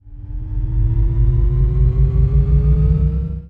acceleration.wav